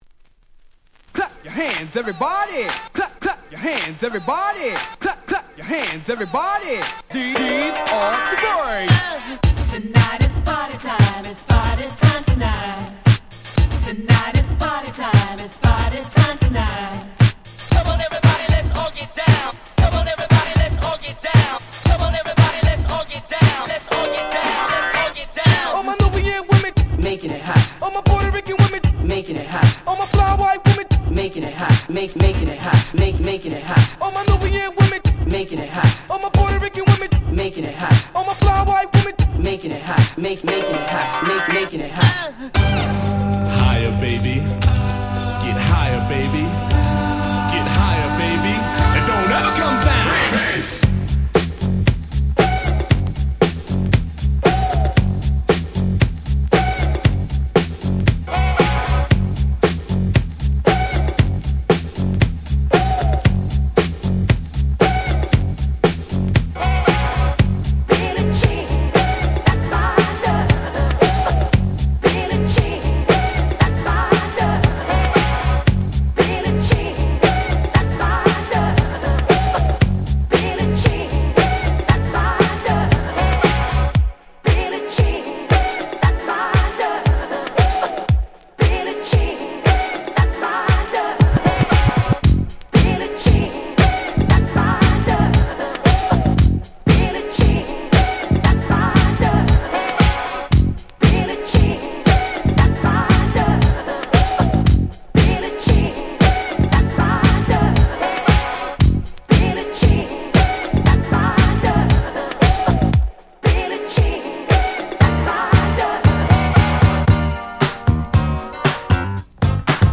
01' Very Nice Party Tracks !!
パーティートラックス ディスコ ダンクラ ダンスクラシックス 80's